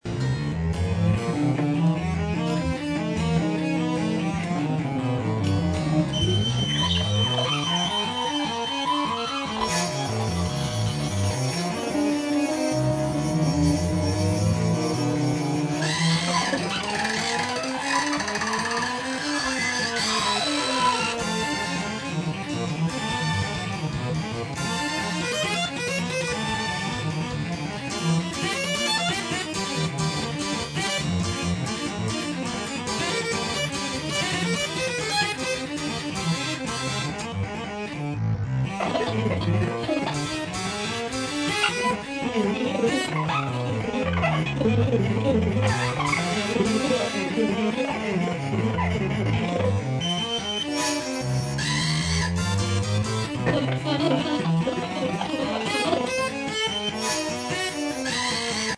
interactive violin bow, solo
whipolin, tenor violin